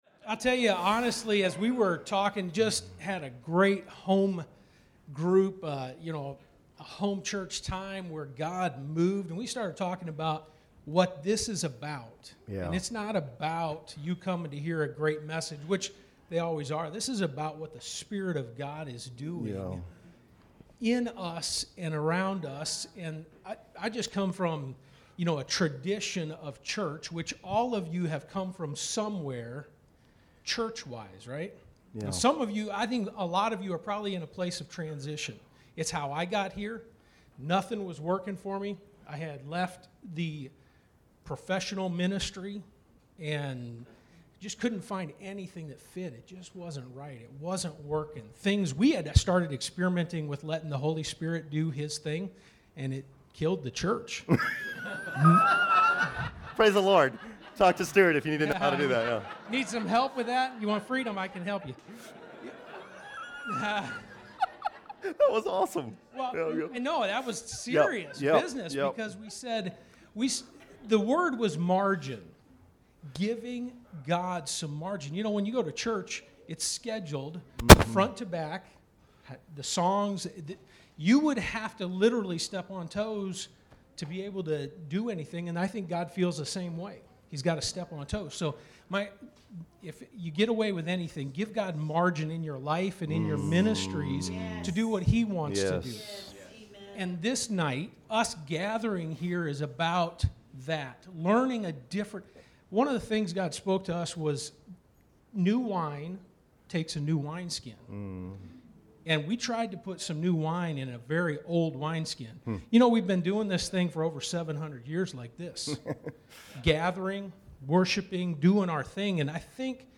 Exhortation